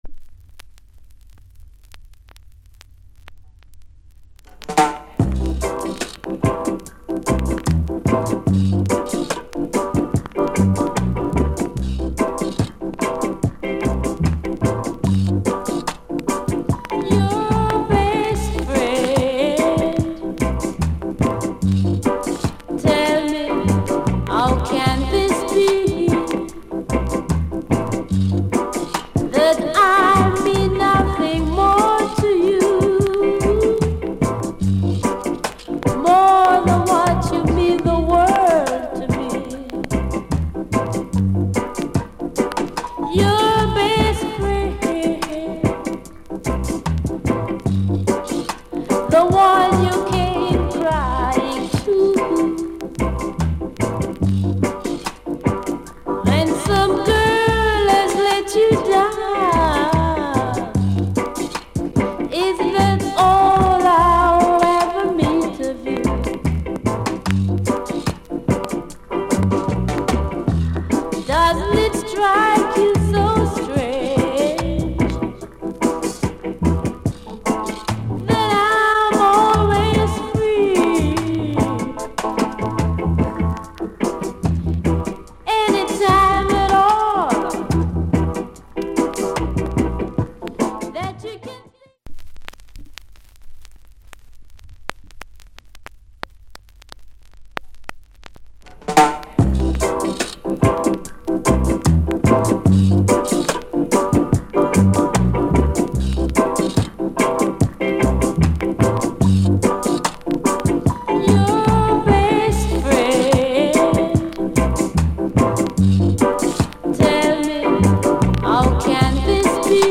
* Rare Lovers Rock!!